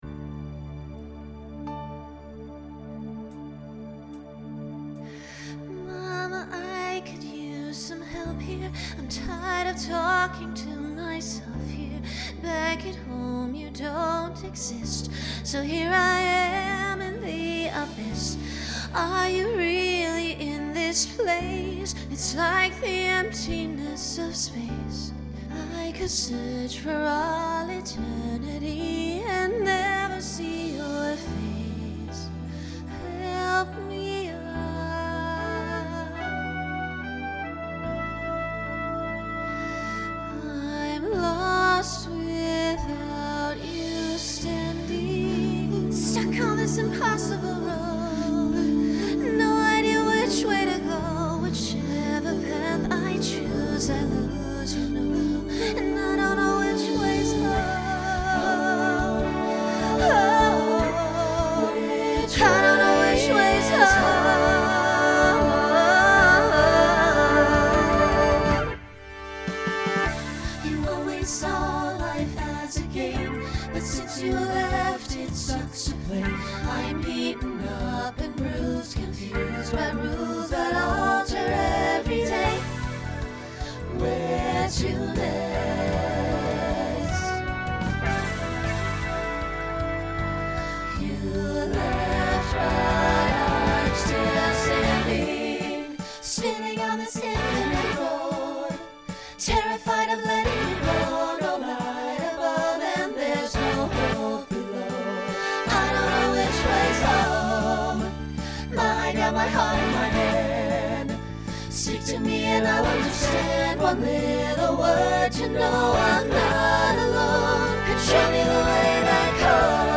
Genre Broadway/Film Instrumental combo
Solo Feature Voicing SATB